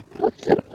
Sound / Minecraft / mob / endermen / idle3.ogg